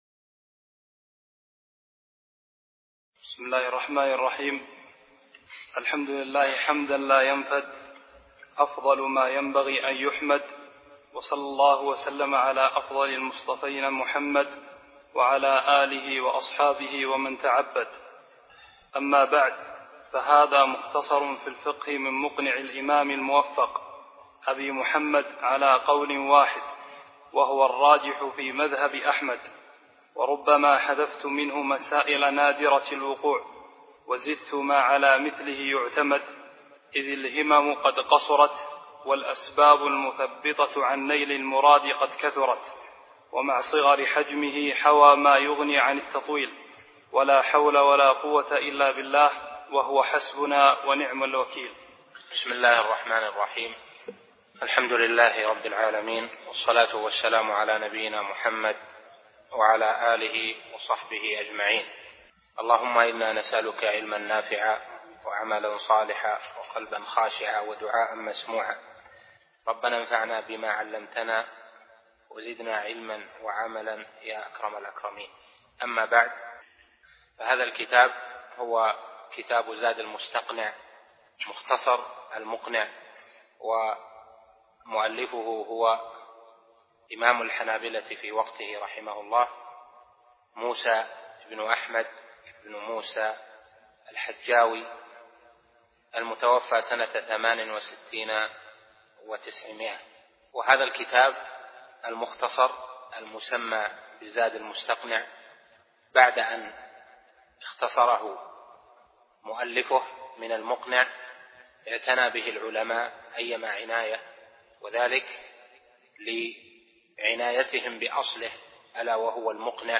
زاد المستقنع في اختصار المقنع شرح الشيخ صالح بن عبد العزيز آل الشيخ الدرس 1